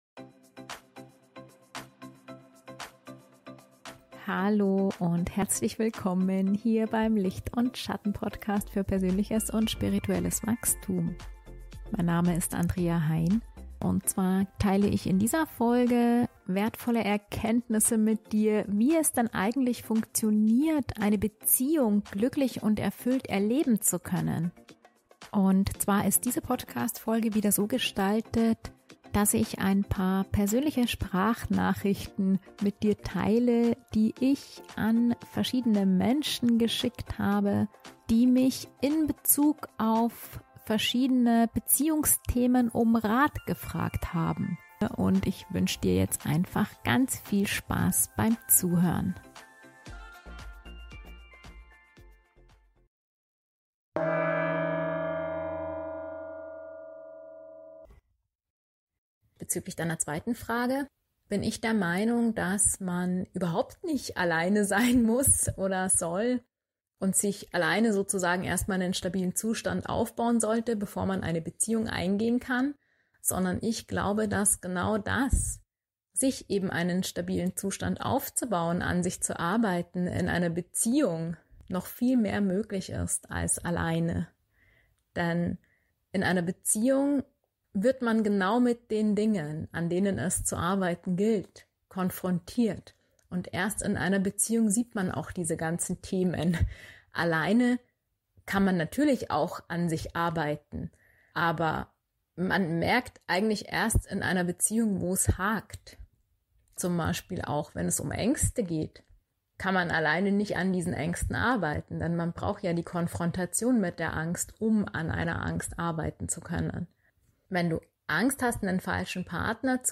In dieser Podcastfolge teile ich nun in Form von Sprachnachrichten wertvolle Erkenntnisse, Tipps und Ratschläge mir Dir, die mir persönlich dabei geholfen haben, mich im Beziehungsleben wohl zu fühlen.